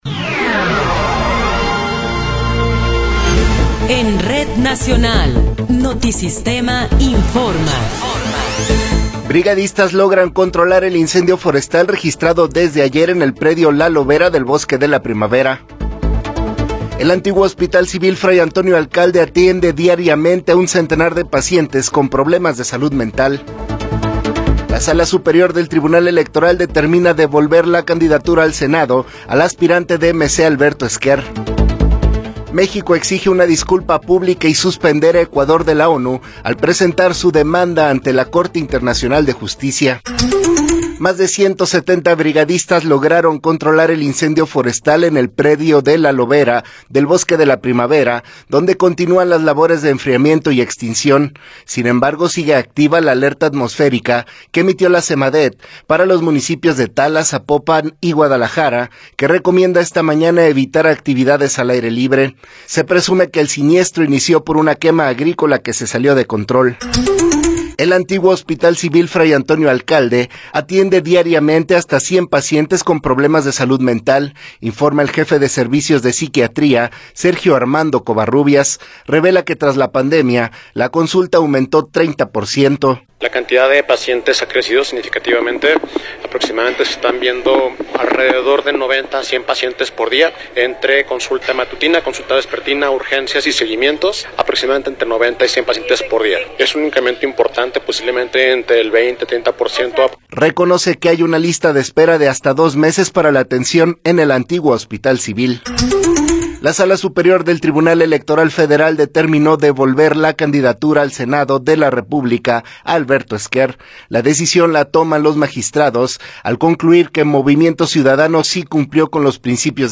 Noticiero 9 hrs. – 11 de Abril de 2024
Resumen informativo Notisistema, la mejor y más completa información cada hora en la hora.